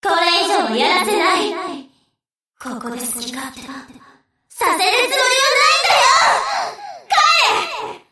Enemy_Voice_Abyssal_Pacific_Princess_Damaged_Introduction.mp3